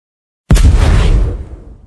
mingwang_attack3.mp3